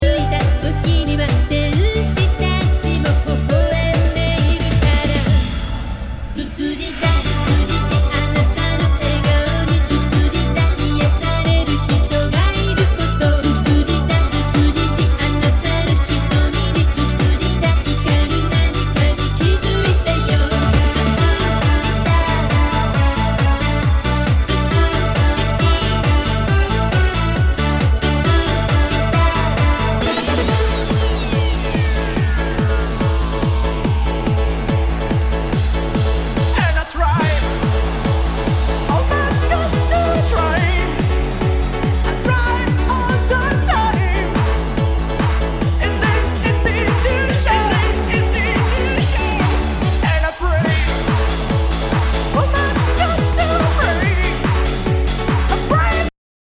HOUSE/TECHNO/ELECTRO
ナイス！ヴォーカル・トランス！
類別 Trance